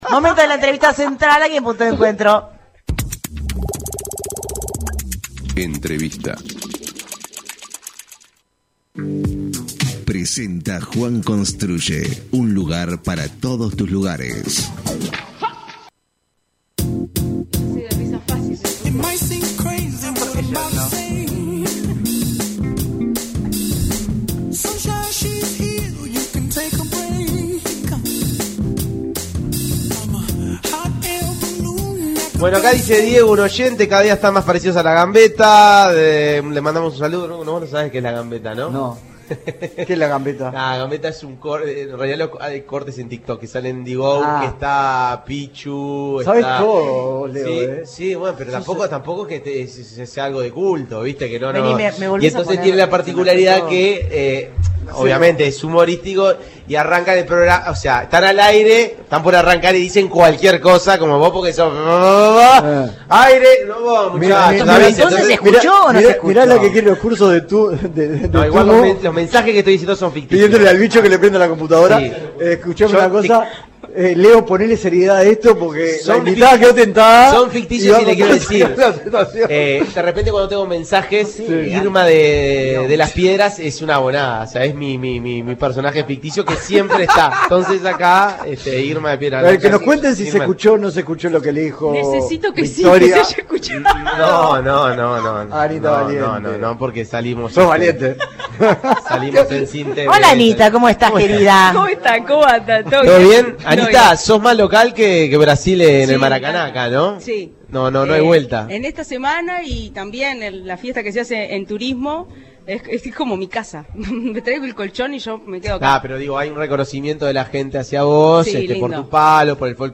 entrevista en Punto de Encuentro